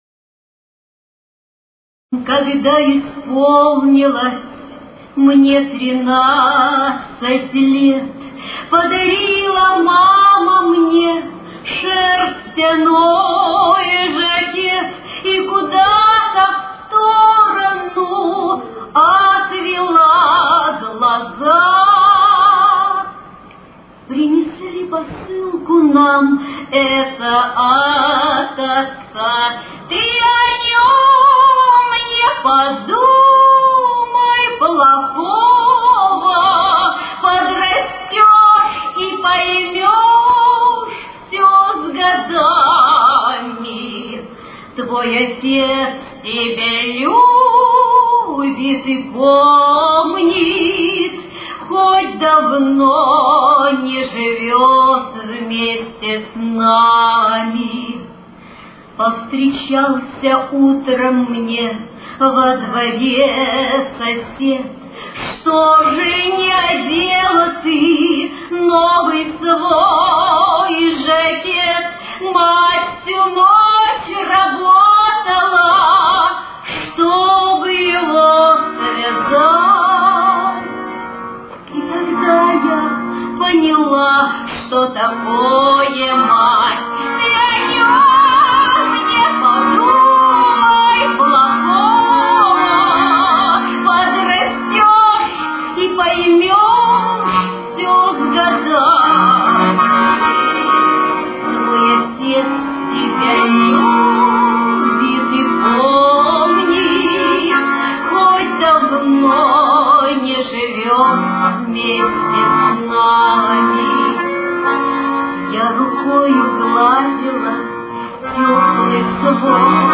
Темп: 56.